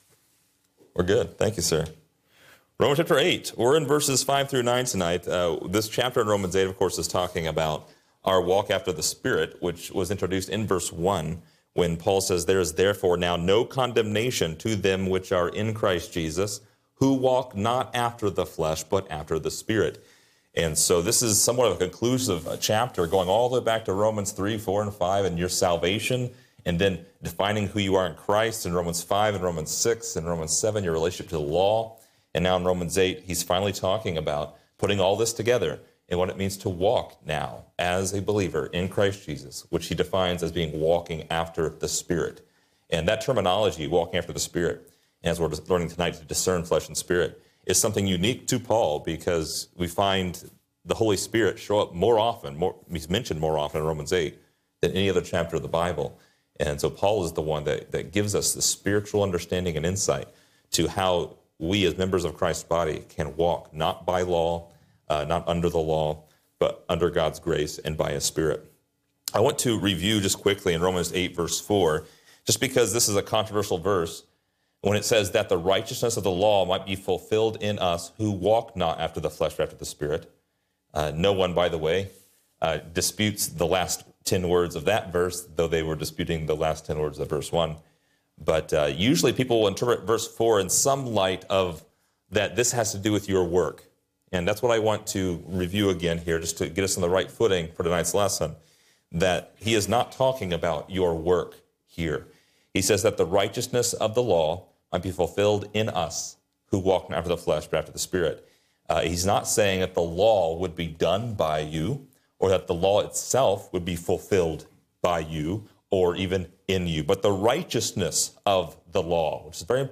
Description: This lesson is part 49 in a verse by verse study through Romans titled: The Mind of Flesh and Spirit.